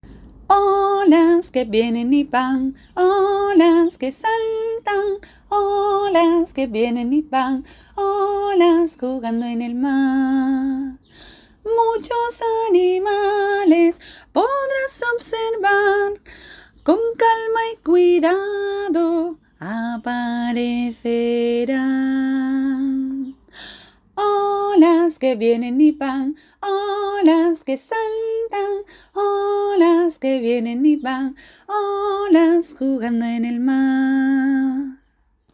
Mixolidio y triple.